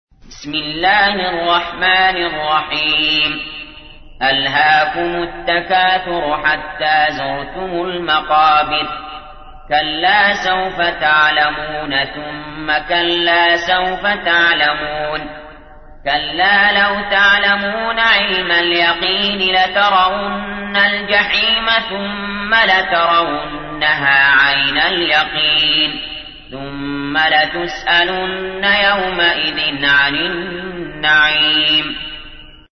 تحميل : 102. سورة التكاثر / القارئ علي جابر / القرآن الكريم / موقع يا حسين